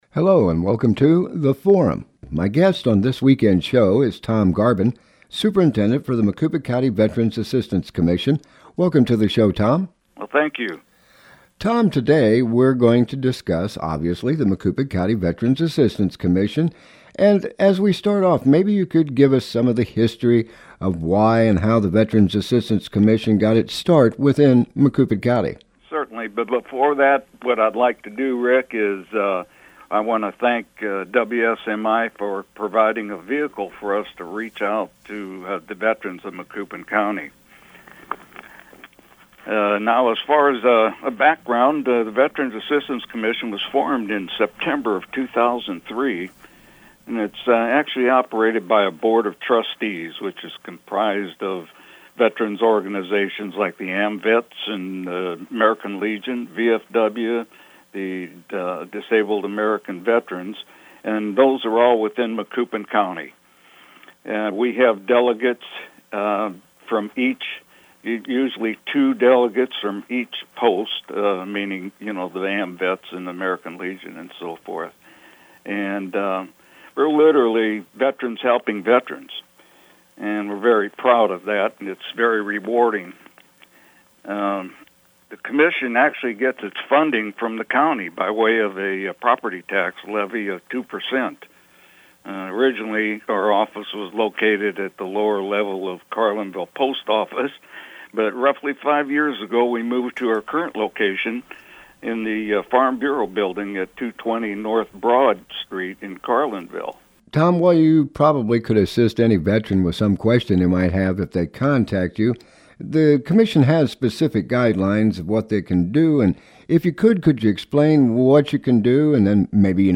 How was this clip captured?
WSMI AM 1540 and FM 106.1. We serve Mid-Illinois.